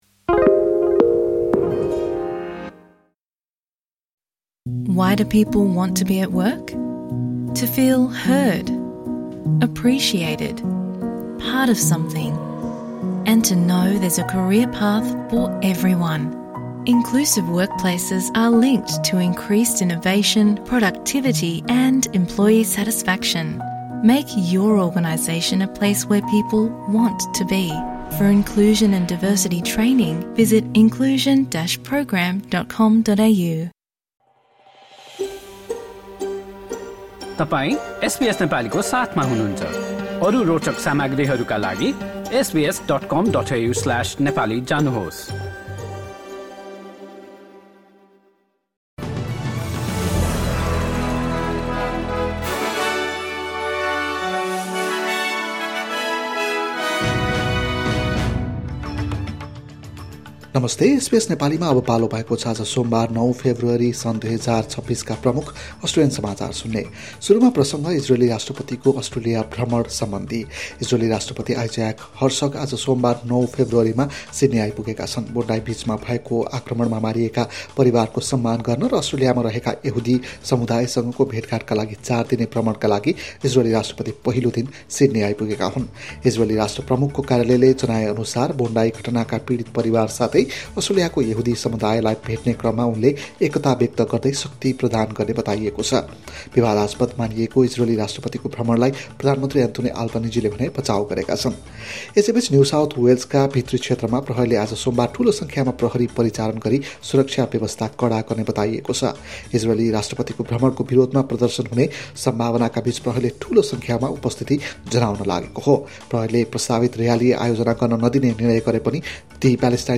एसबीएस नेपाली प्रमुख अस्ट्रेलियन समाचार: सोमवार, ९ फेब्रुअरी २०२६